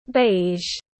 Màu be tiếng anh gọi là beige, phiên âm tiếng anh đọc là /beɪʒ/.
Beige /beɪʒ/